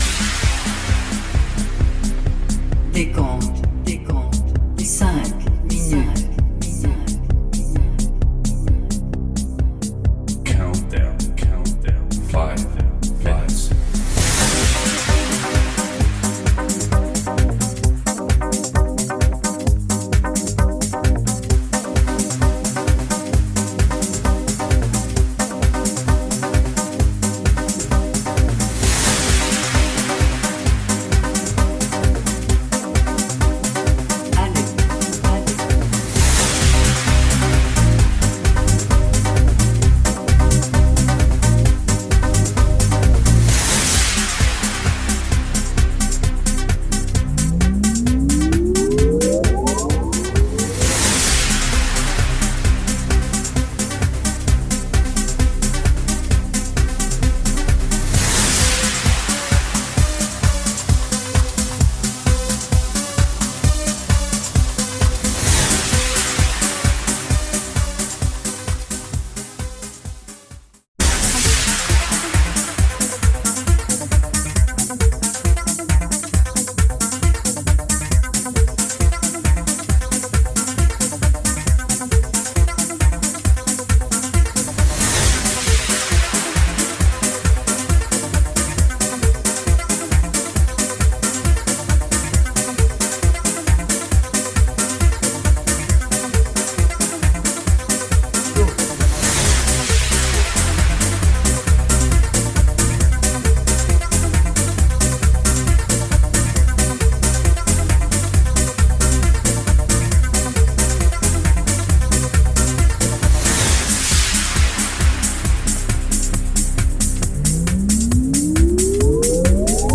Spoken countdown also included.